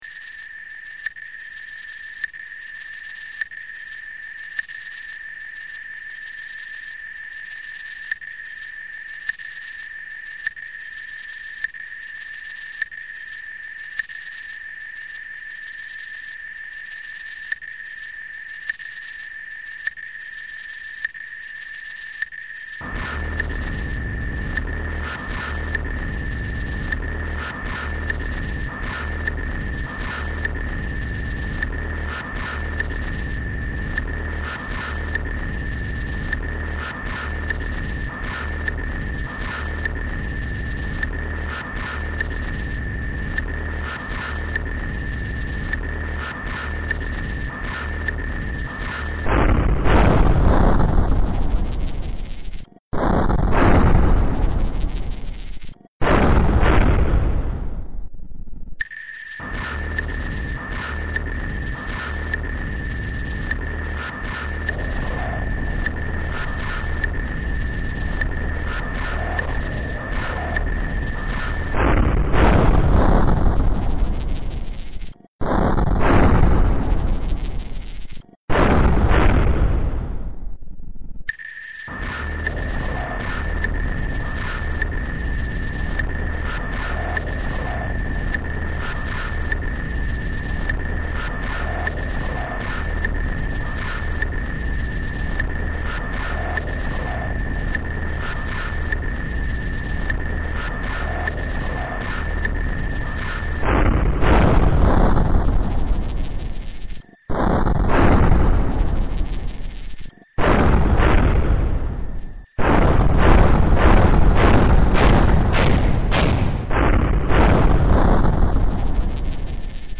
File under: Ambient / Industrial / Harsh Electronics
harsh and distorted assaults